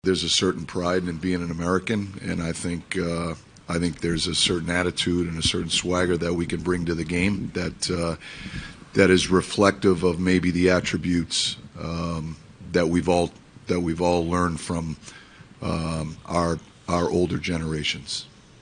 Sullivan says the Americans are proud to represent their country.